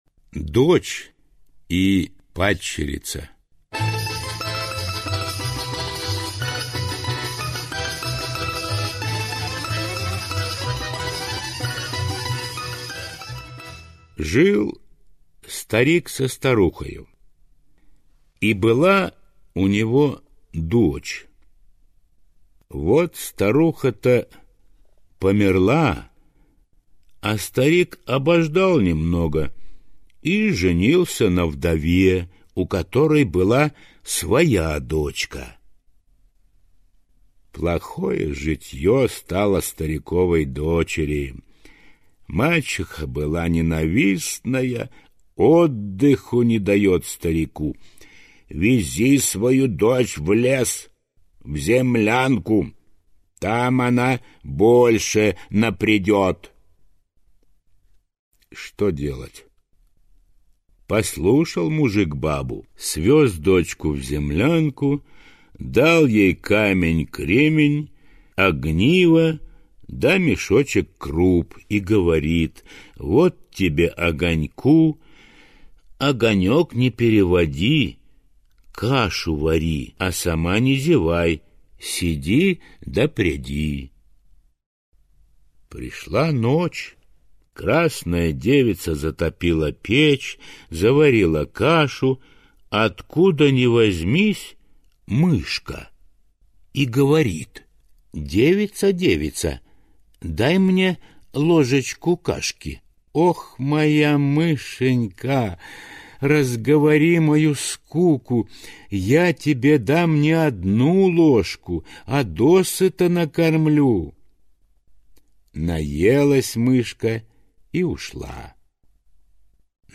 теги: аудиосказка, сказка, русская народная сказка